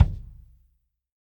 Kick Zion 2.wav